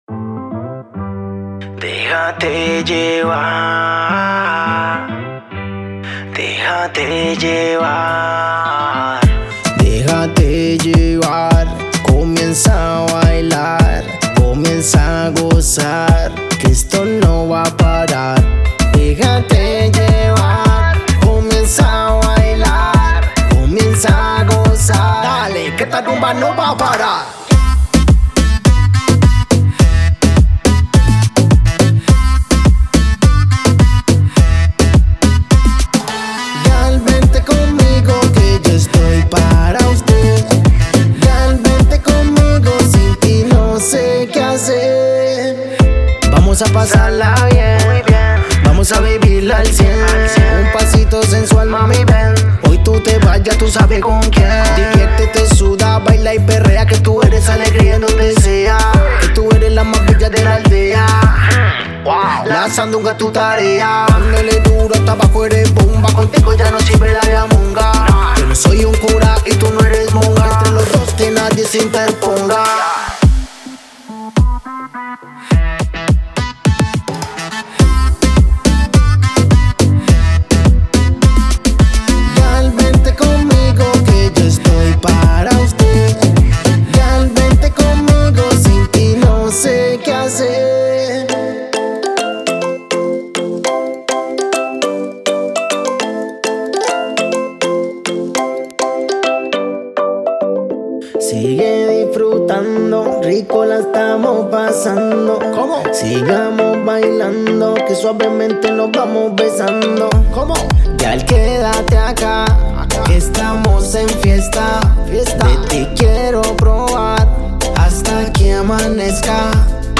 Sample，Acapellas，Raps和Presets。
该包包含moombahton鼓和打击乐器，深贝斯乐队以及有趣的旋律和和弦结构